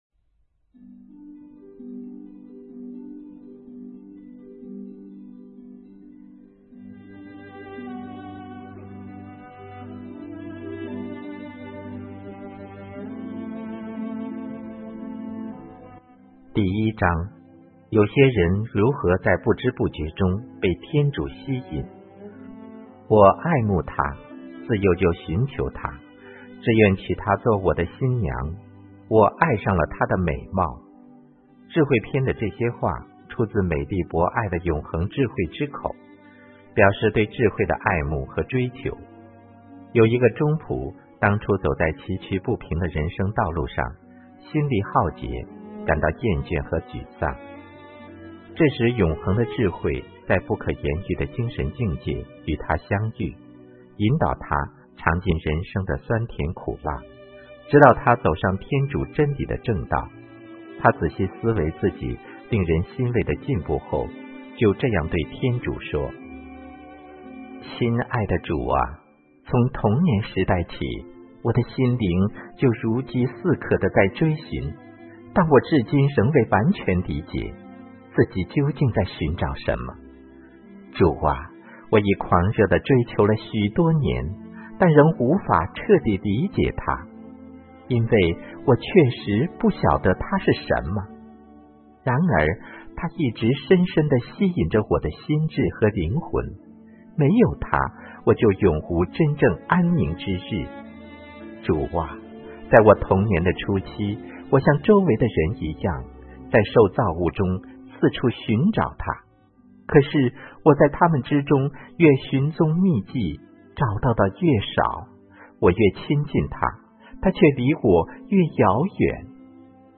音频/有声阅读/永恒的智慧小册 • 在线资料库